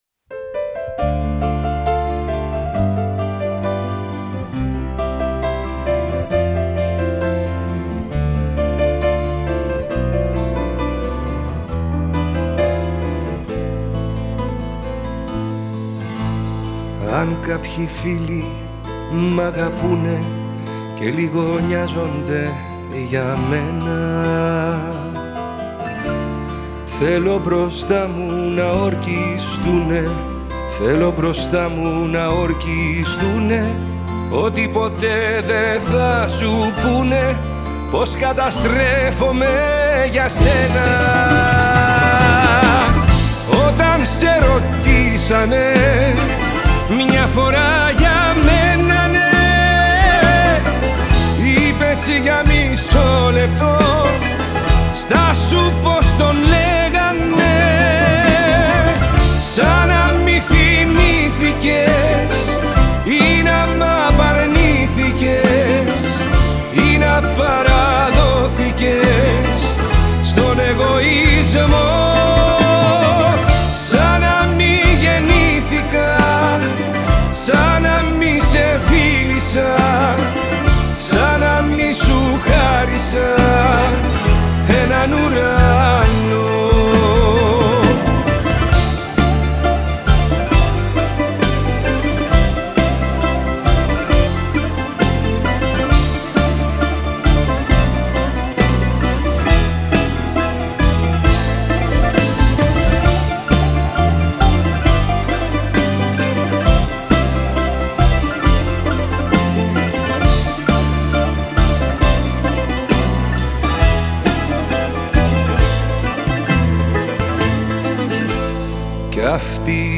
ελληνικό τραγούδι